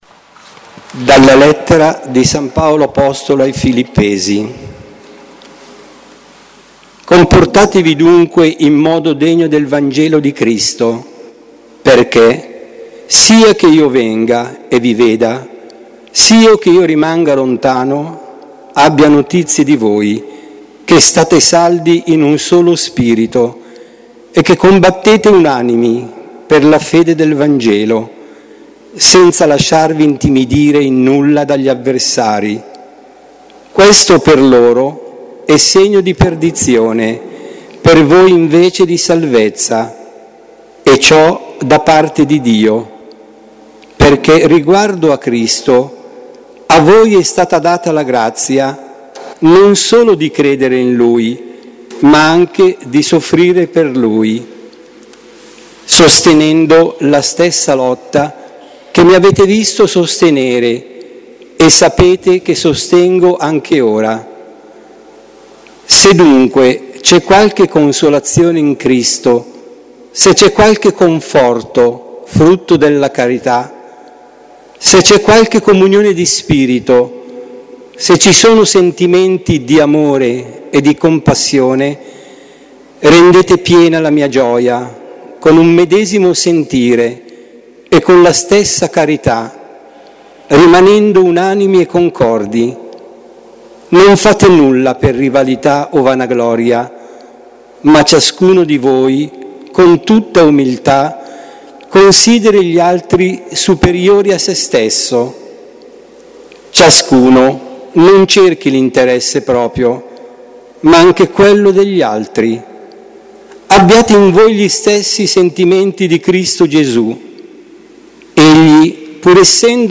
Lettura_LD19e20_dic.mp3